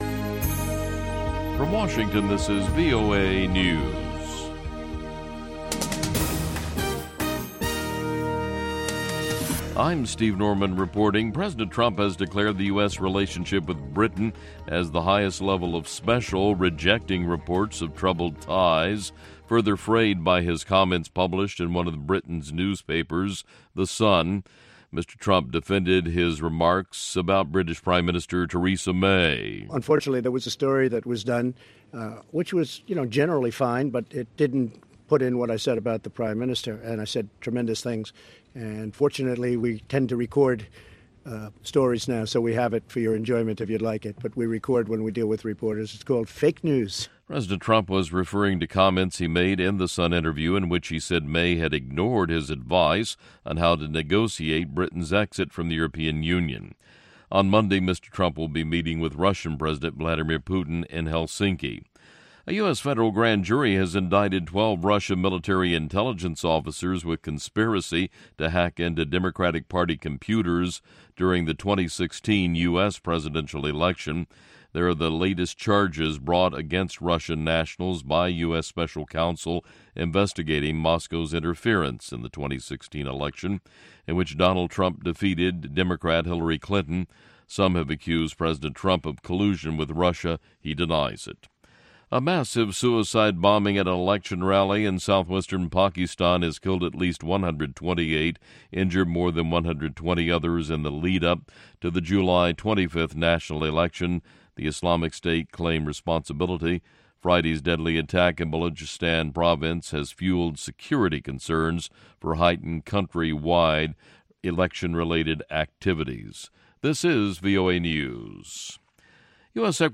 exclusive interviews
rare recordings